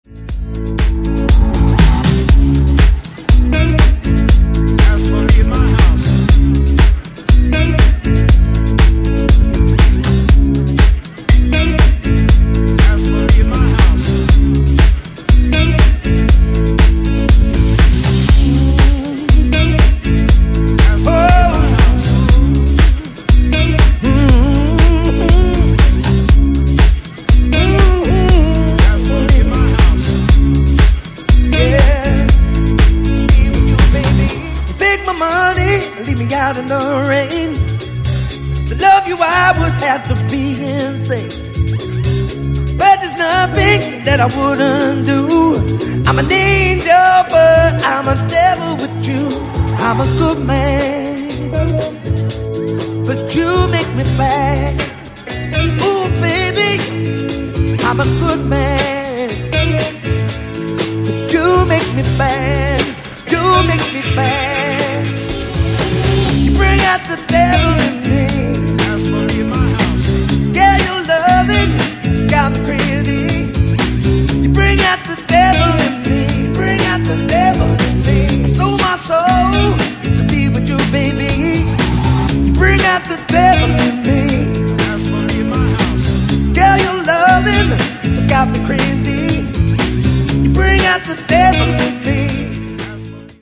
EXTENDED MIX
ジャンル(スタイル) HOUSE / DEEP HOUSE / DISCO HOUSE